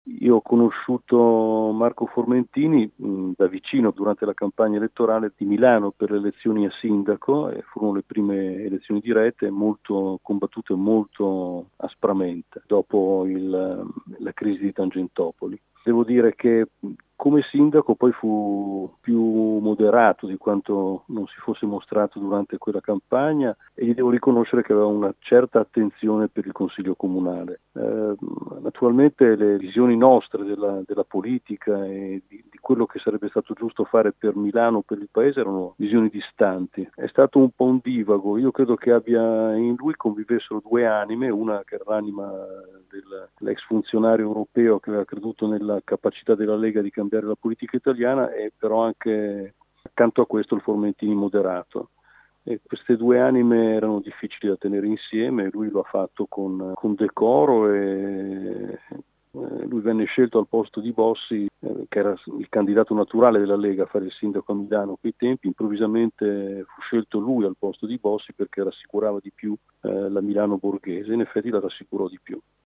Il ricordo di Nando Dalla Chiesa, che di Formentini fu lo sfidante alle elezioni Comunali di Milano.